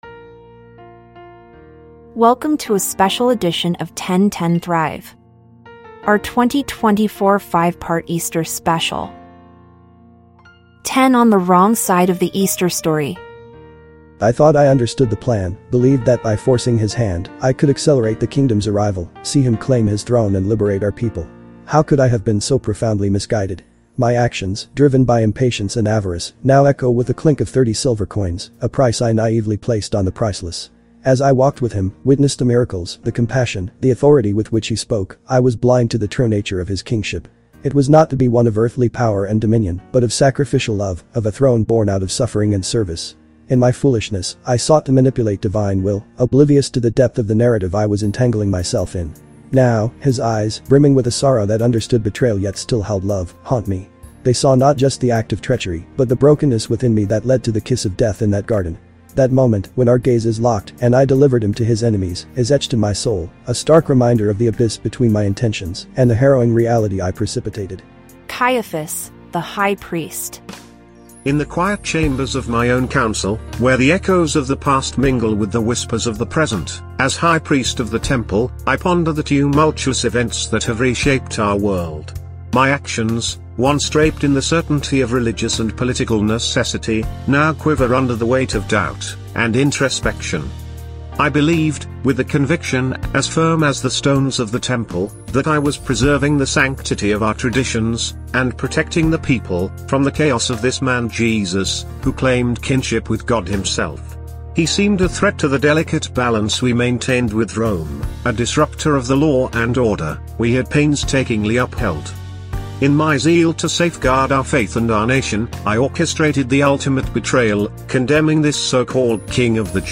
In monologues, mostly tinged with regret, they share their intentions and how these may have gotten them in trouble.